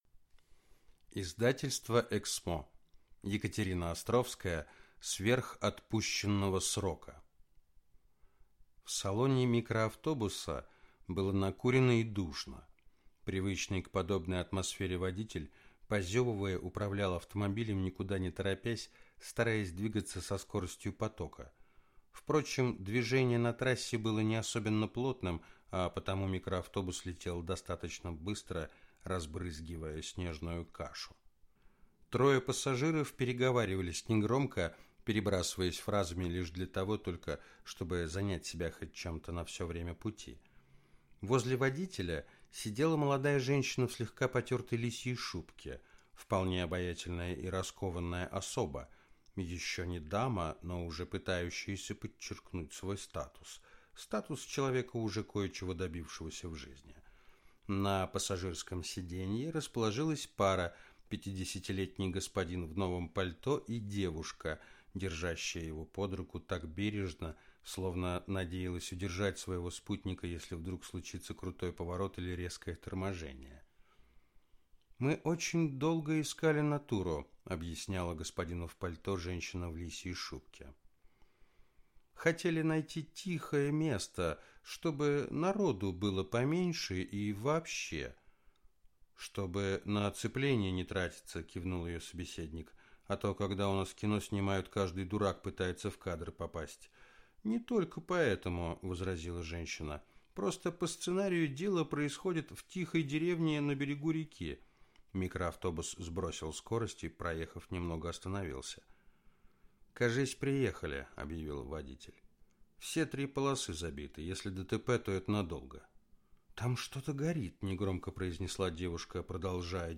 Аудиокнига Сверх отпущенного срока | Библиотека аудиокниг